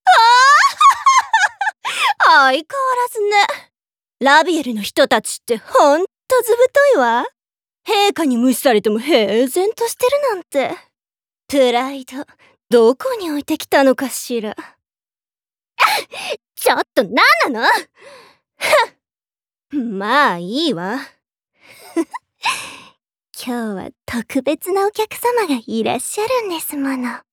ボイスサンプル2